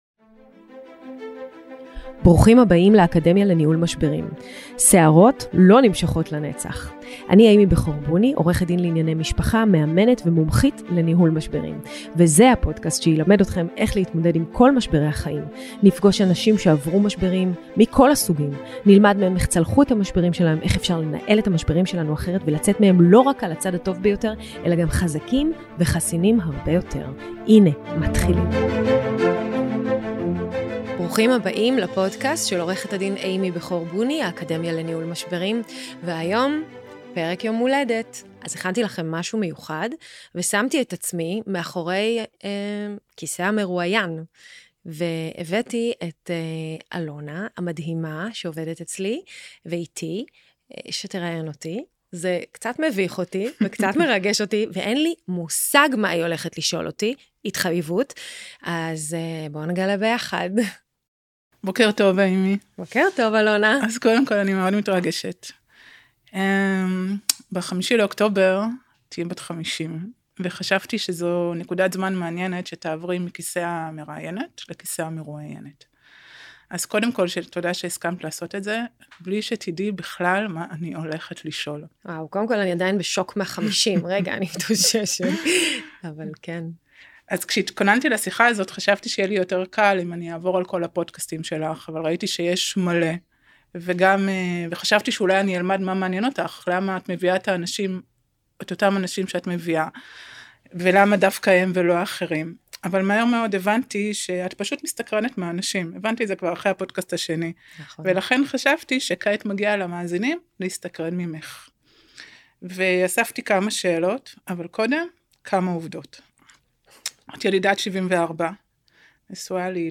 ספיישל יום הולדת - ראיון אישי